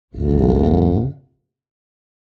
sounds / mob / sniffer / idle2.ogg